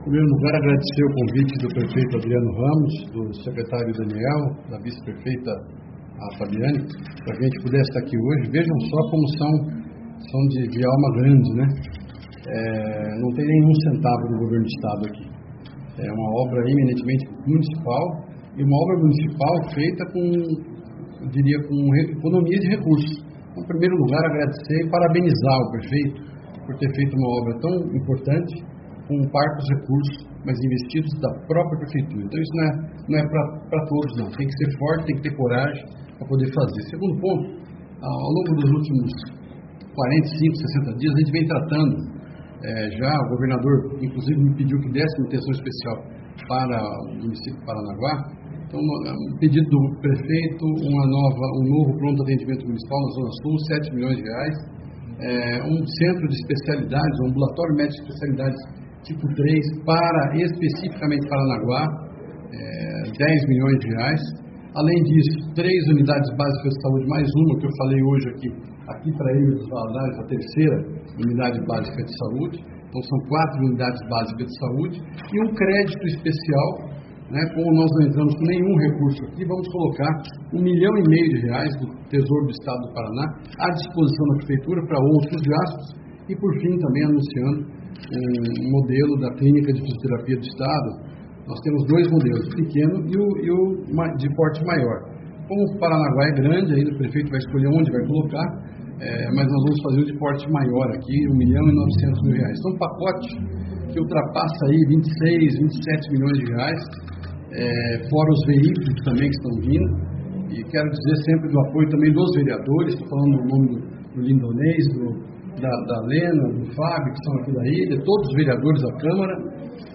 A cerimônia contou com a presença do secretário estadual de Saúde, Beto Preto, além de autoridades municipais, vereadores e representantes da comunidade. Durante o evento, o secretário destacou a importância da obra, ressaltando que se trata de um investimento realizado com recursos próprios do município.
SONORA-BETO-PRETO.mp3